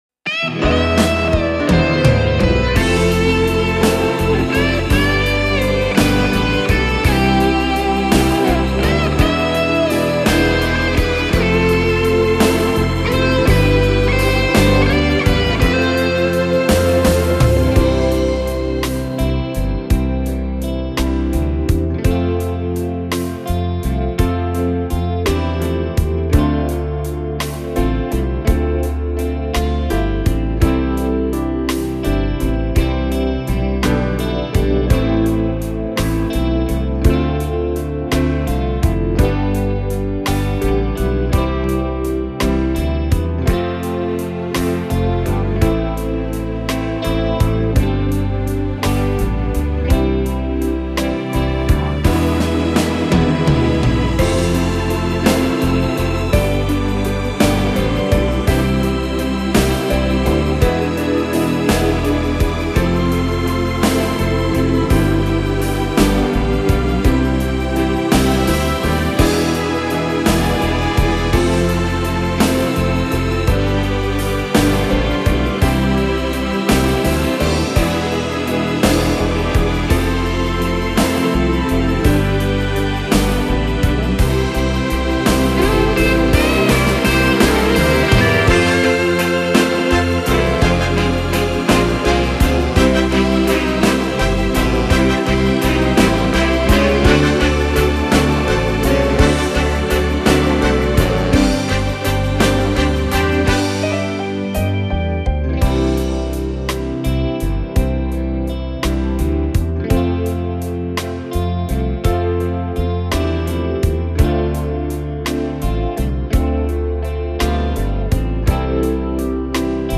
Genere: Terzinato
Scarica la Base Mp3 (3,44 MB)